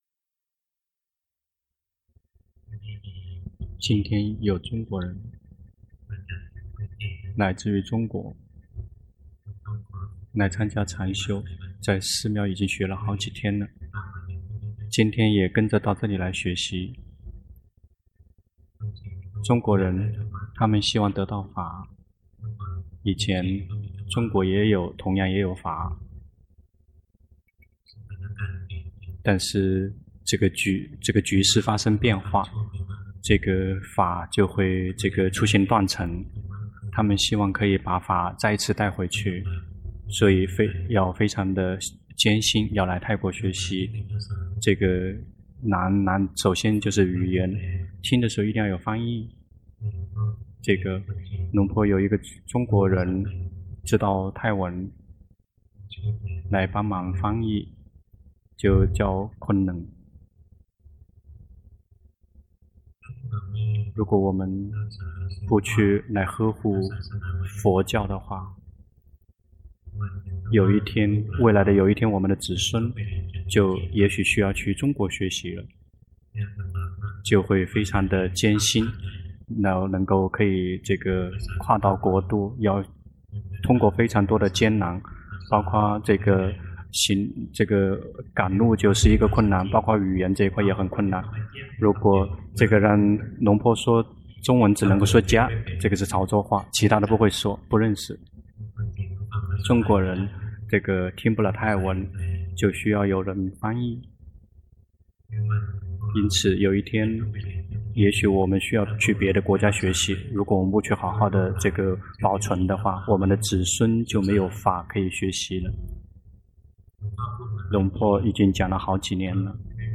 長篇法談｜法，以心傳心